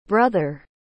A pronúncia é /ˈbrʌðər/, onde o “th” tem um som aspirado, meio que entre o “d” e o “z”.